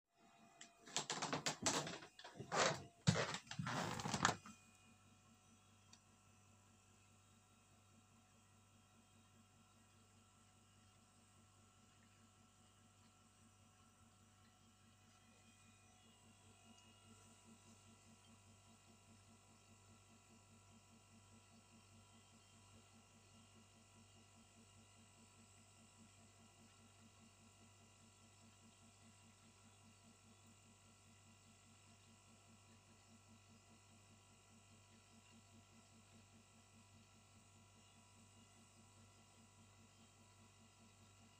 Klepe lednice, co to může být?
Obyčejná, kompresorová, zapínací a vypínaci.
Ale klepe kompr, nějaká diagnostika?
lednicekleppe.aac